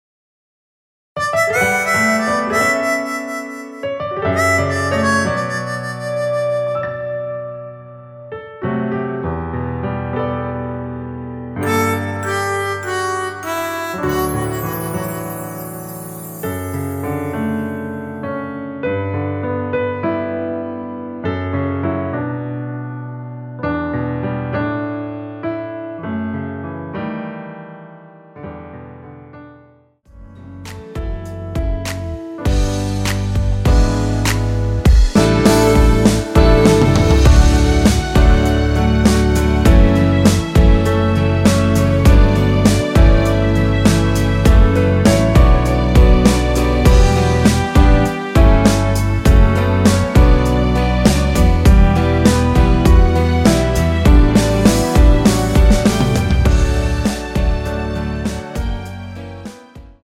원키에서(-5)내린 MR입니다.
앞부분30초, 뒷부분30초씩 편집해서 올려 드리고 있습니다.